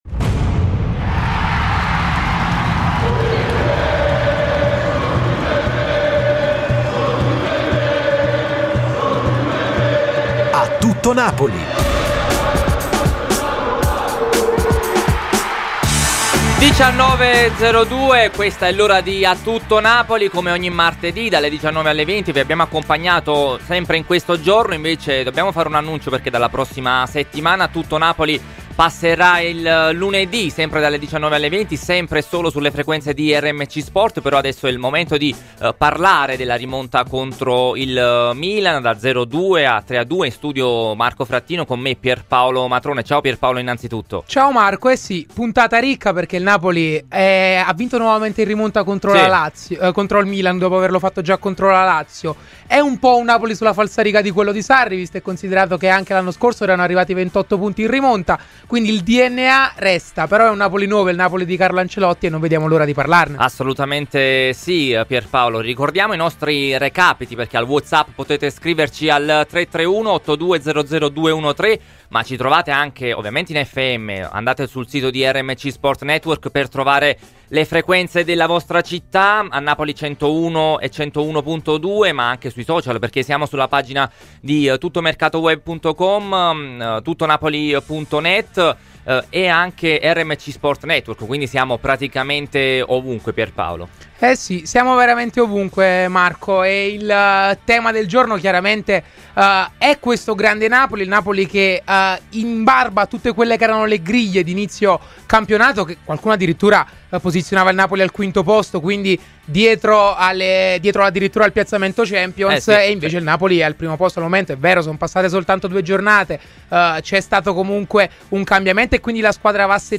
Rubrica di approfondimento sul Napoli … continue reading 9 Episoden # Notizie sportive # Calcio # Diretta # Calciomercato # Cronaca # Interviste # Sport # TMW Radio # segnalazioni # WEBRADIO